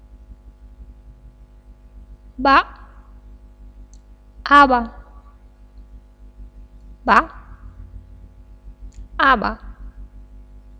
Voiced Sounds of the Romanian Language
Consonants - Speaker #3
b_ba_aba3_[24b].wav